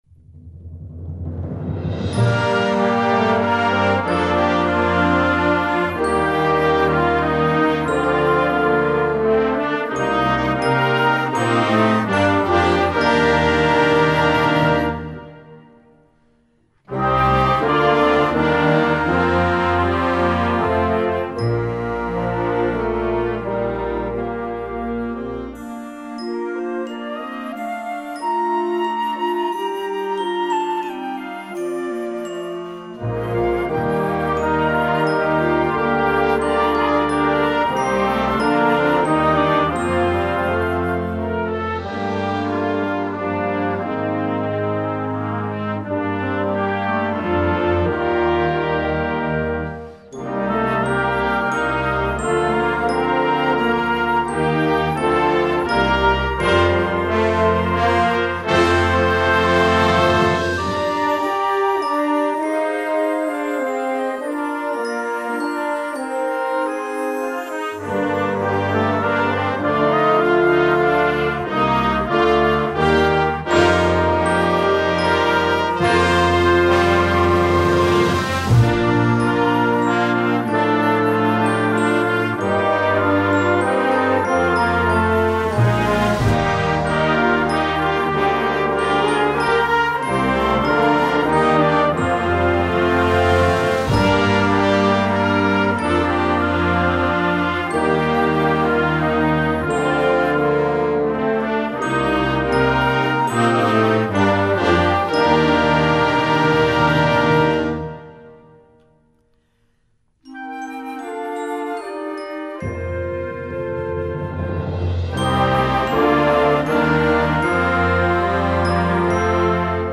Gattung: Jugendwerk
Besetzung: Blasorchester
Mit reichhaltigen Harmonien und lyrischen Melodien zeichnet
Dieses ruhige und ausdrucksstarke Stück